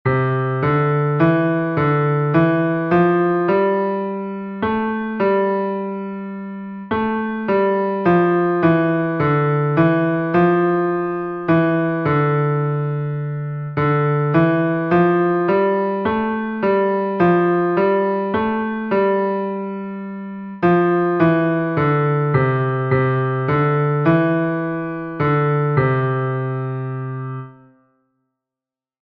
Exercise 4: 2/4 time signature